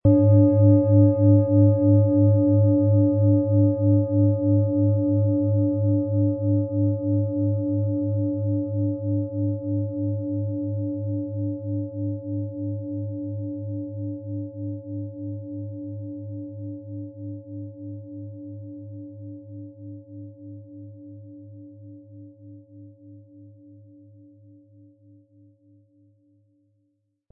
Planetenschale® Löse Dich von alten Strukturen & Altes aufgeben mit Uranus, Ø 22,9 cm, 1100-1200 Gramm inkl. Klöppel
Von Meisterhand hergestellte Planetenton-Klangschale Uranus.
Sie möchten den schönen Klang dieser Schale hören? Spielen Sie bitte den Originalklang im Sound-Player - Jetzt reinhören ab.
Aber dann würde der ungewöhnliche Ton und das einzigartige, bewegende Schwingen der traditionellen Herstellung fehlen.
Sanftes Anspielen mit dem gratis Klöppel zaubert aus Ihrer Schale berührende Klänge.
MaterialBronze